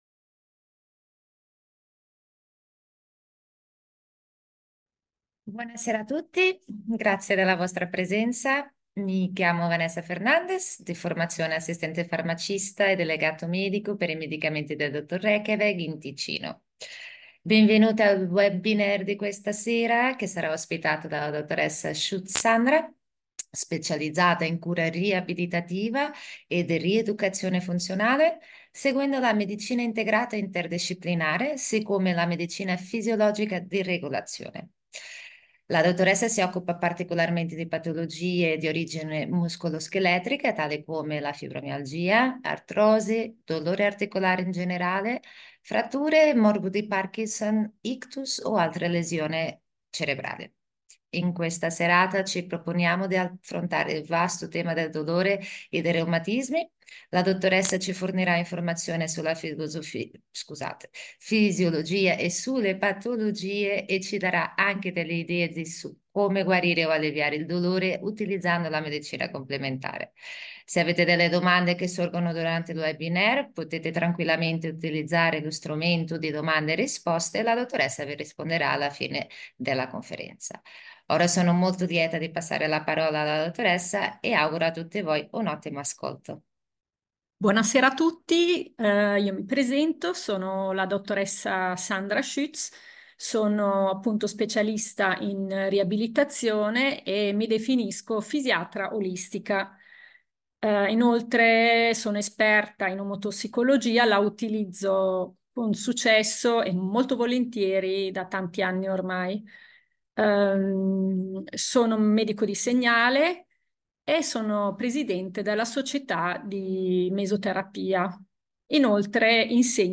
Audio del webinar